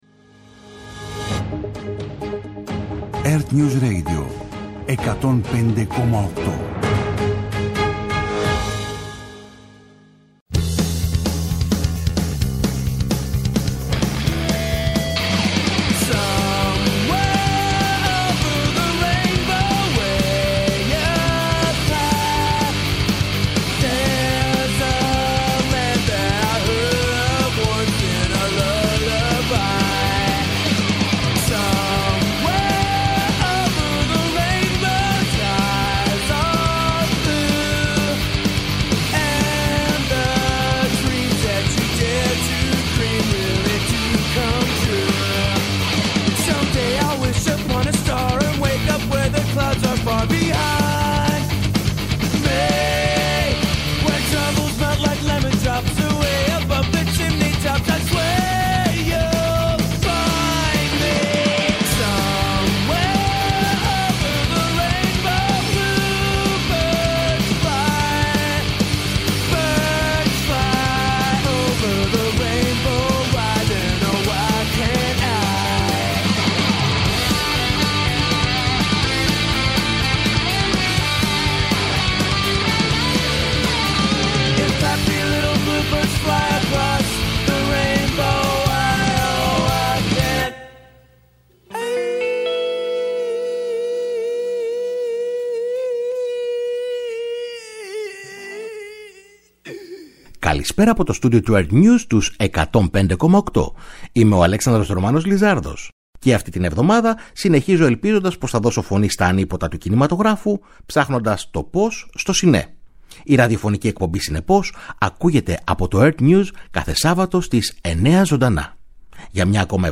Με τη νέα ραδιοφωνική του εκπομπή «Cine-Πώς», που θα μεταδίδεται κάθε Σάββατο στις 21.00, παρουσιάζει την cine-επικαιρότητα και επιχειρεί να λύσει – ή έστω να συζητήσει – τις κινηματογραφικές μας απορίες.